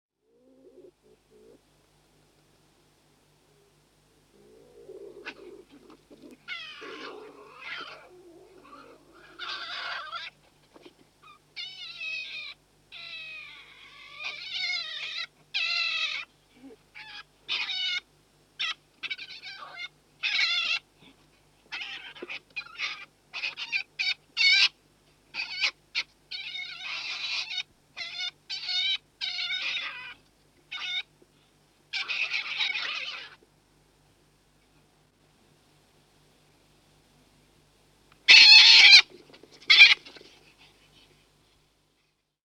Звуки куницы
Сосновая куница запечатлена в дикой природе